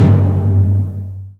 TIMP.wav